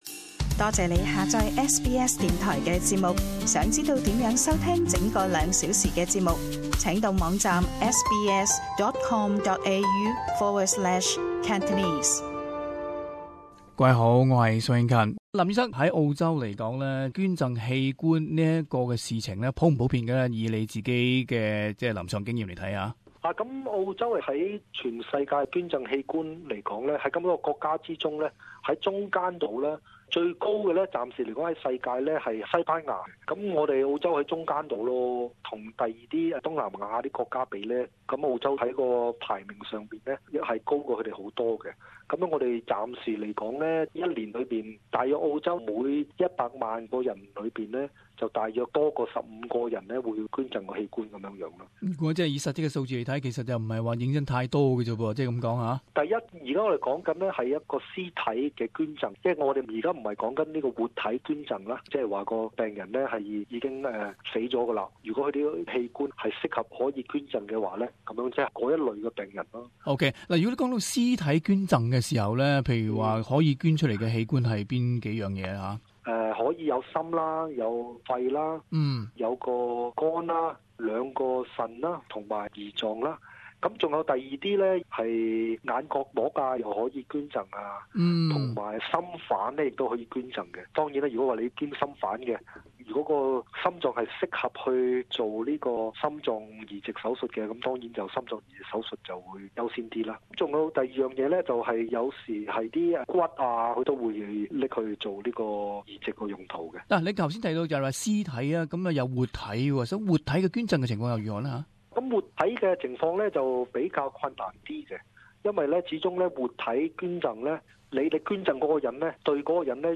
【社團專訪】器官捐贈在澳洲的接受程度有多少？